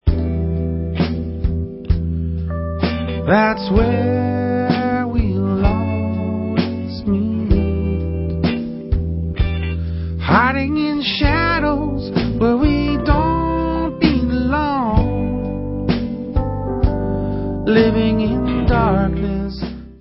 NEW 2005 STUDIO ALBUM